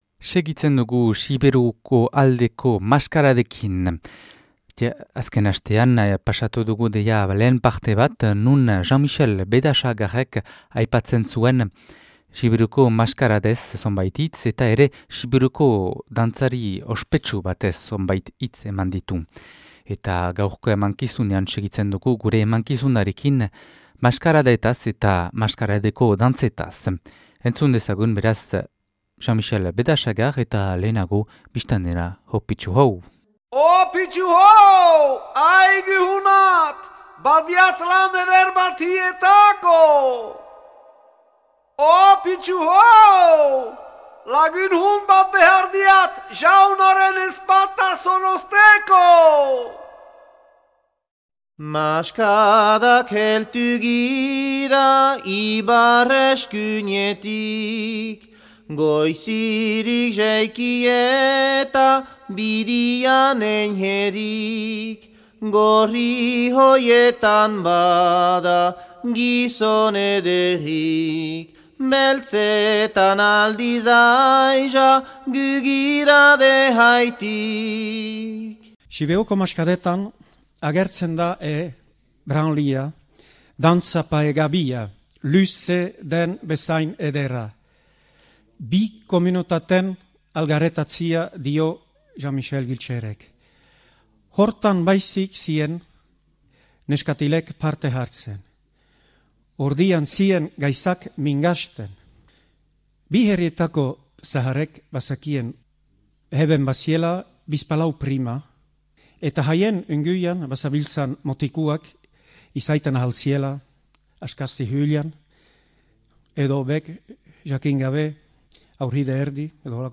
mintzaldia.
(2006. Azaroaren 11an grabatua Barkoxen)